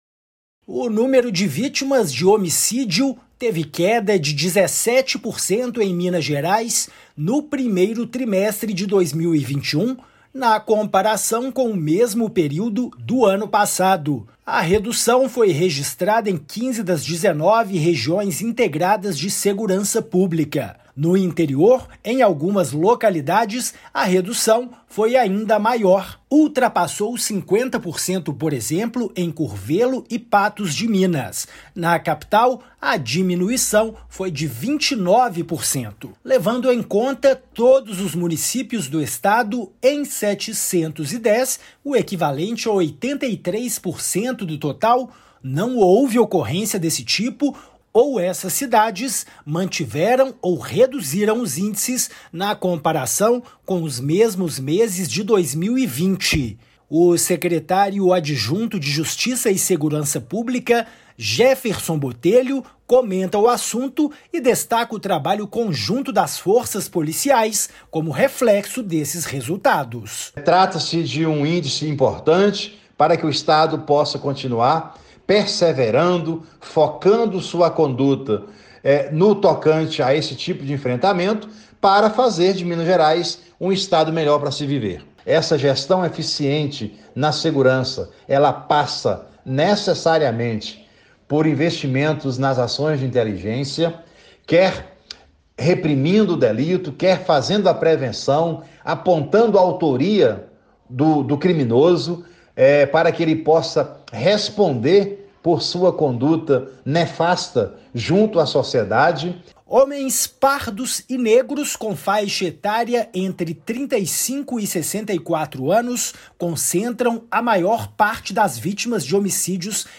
[RÁDIO] Homicídios caem 17% em Minas no primeiro trimestre
Número de vítimas reduziu mais da metade em algumas regiões; em BH índice caiu 29,4%. Ouça a matéria de rádio.